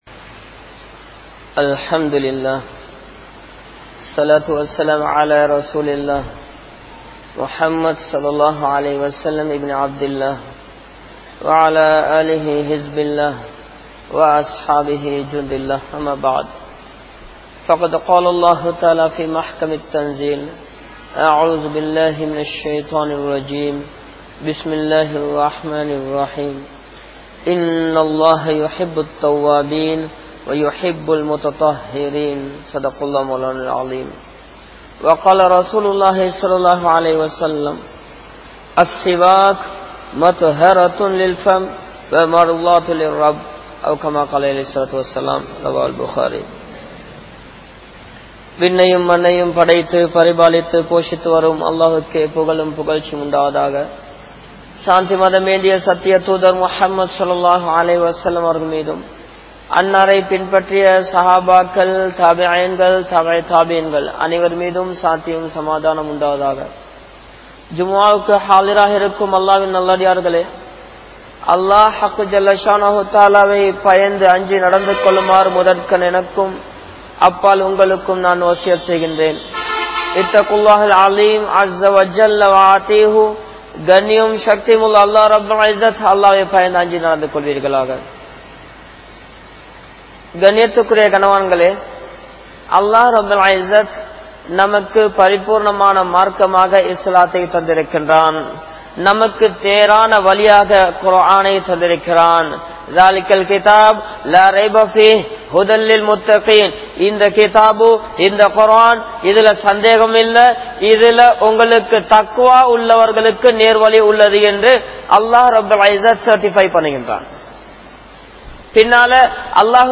Importance of Miswak | Audio Bayans | All Ceylon Muslim Youth Community | Addalaichenai